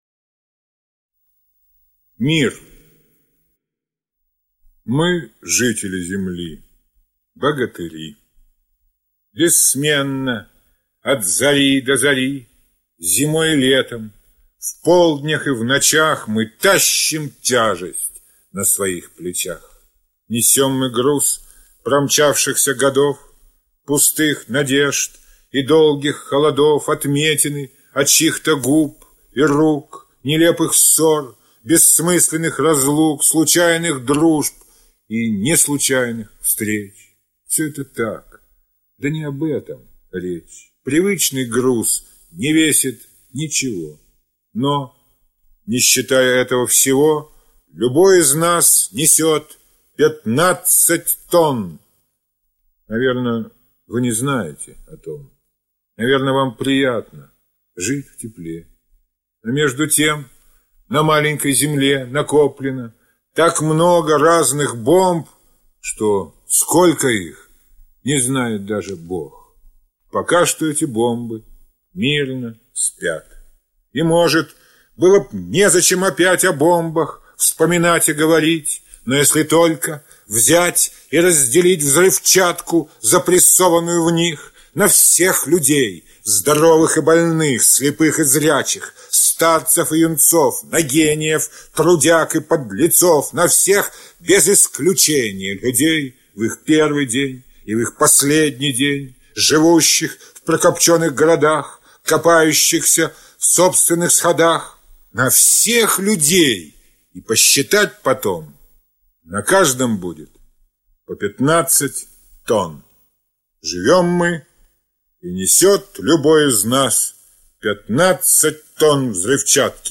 Rozhdestvenskiy-Mir-chitaet-avtor-stih-club-ru.mp3